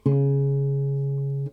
Eine virtuelle Gitarre mit Audiofunktionen zum Üben aller Notennamen und zum Hören aller Töne auf dem Griffbrett einer klassischen Gitarre
A_cis.mp3